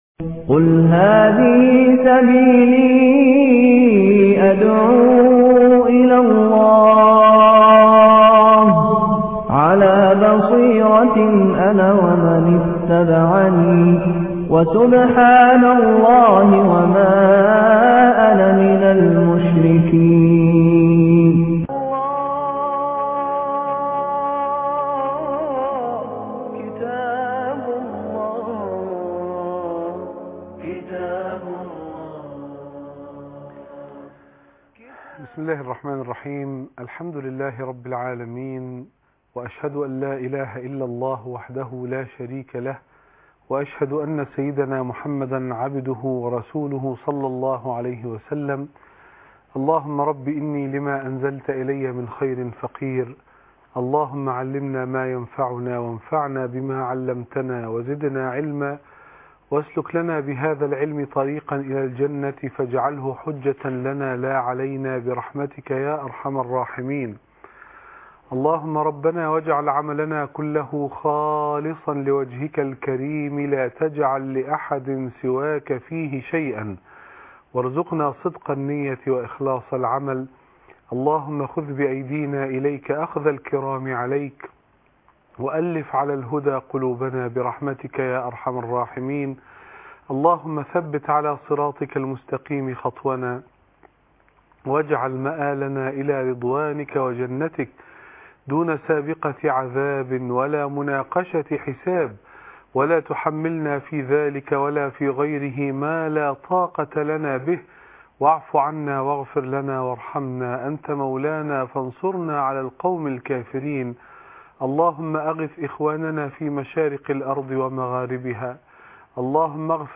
معركة تربية النفس باجر 50 صحابى ( 28/6/2010 ) لقاء - الشيخ حازم صلاح أبو إسماعيل